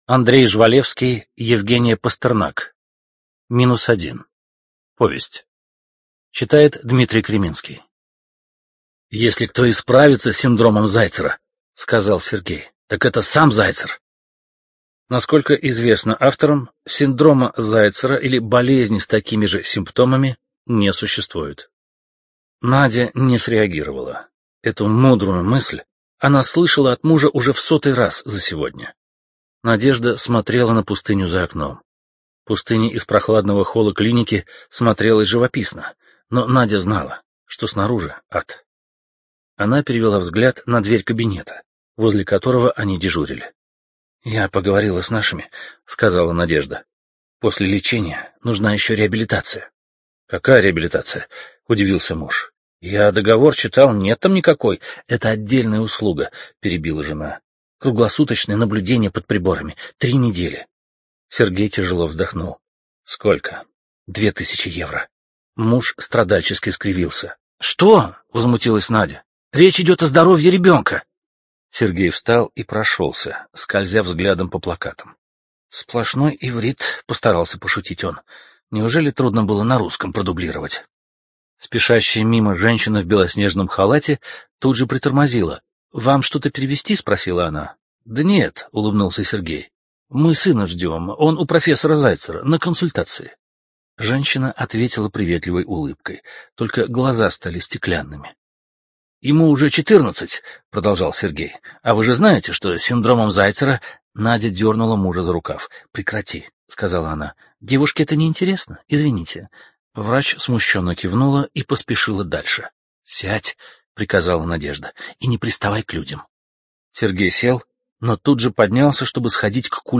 Студия звукозаписивимбо